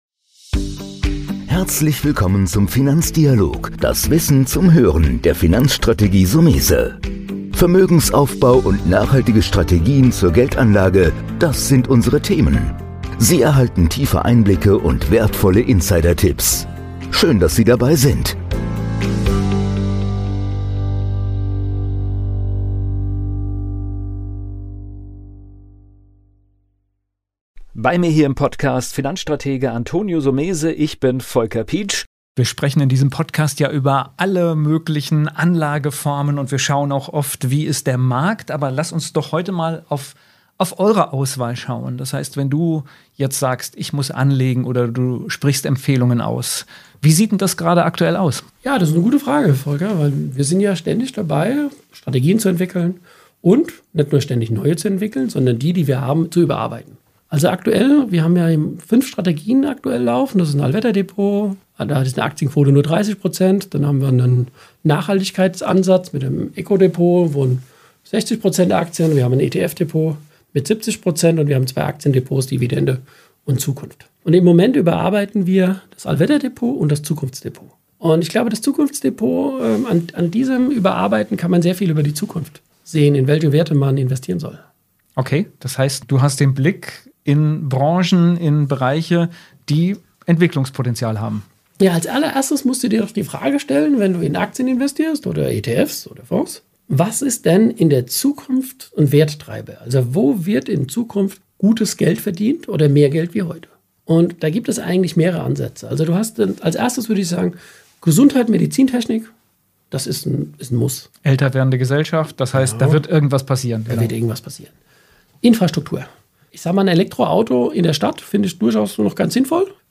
Im Dialog mit unseren Gästen öffnen wir das Fenster zu Wirtschaft, Kapitalmarkt und Finanzwelt. Wir geben tiefe Einblicke und wertvolle Insidertipps.